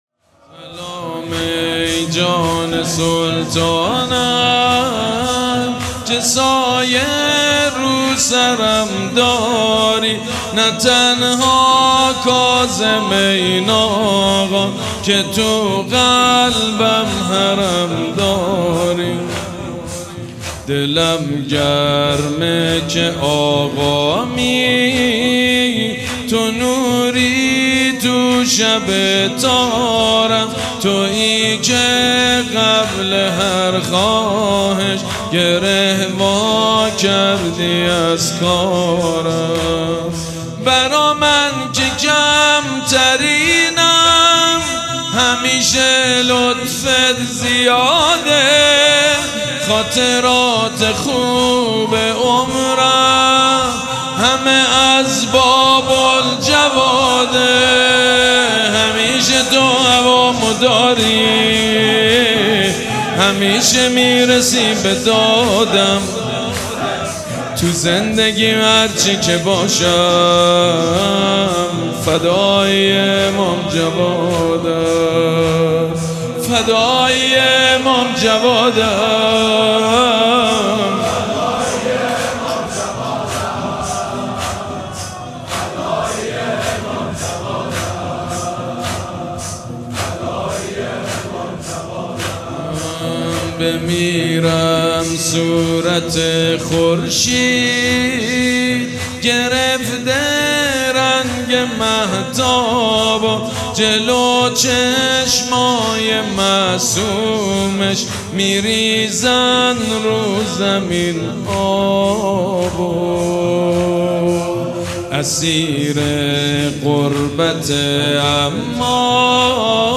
مداحی «سلام ای جان سلطانم» با نوای مجید بنی فاطمه (کلیپ صوت، متن)
دانلود مداحی «سلام ای جان سلطانم» به مناسبت شهادت حضرت امام جواد علیه السلام با نوای حاج سید مجید بنی فاطمه همراه با صوت و متن